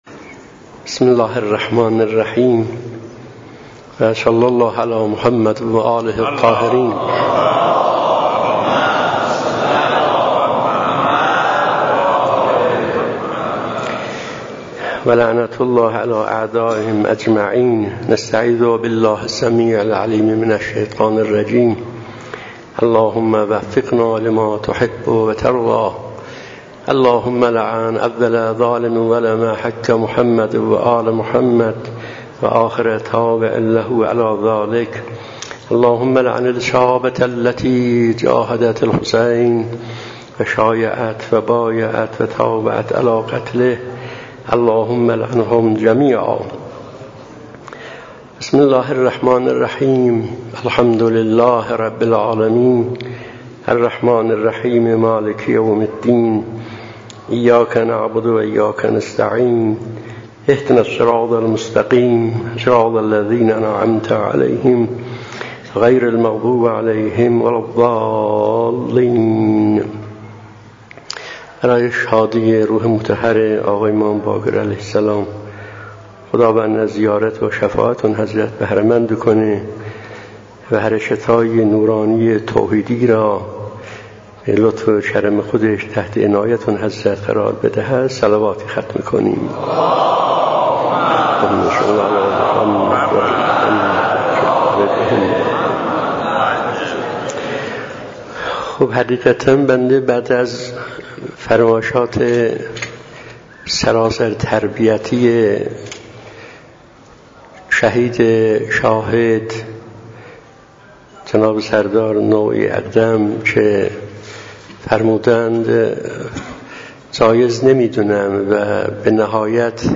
✅ جلسه ماهانه- جمعه اول هر ماه قمری 🔰موضوع: ذکر احادیثی درباره اخلاص
🌎مکان: تبریز- محله خطیب-مسجد امام خمینی(ره)